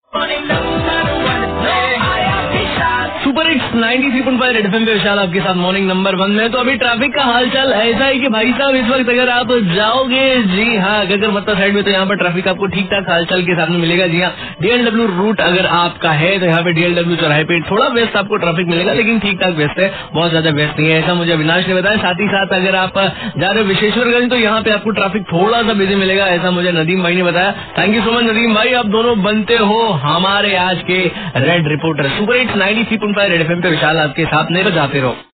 traffic update